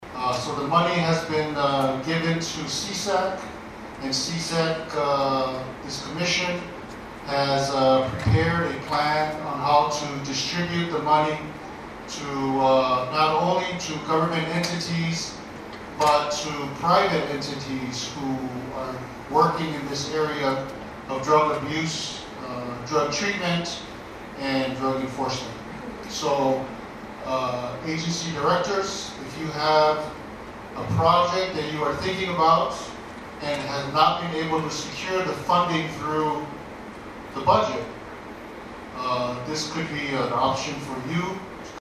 Lt. Governor Talauega also revealed at the cabinet meeting that ASG received close to $1 million in a nationwide settlement with major pharmaceutical companies.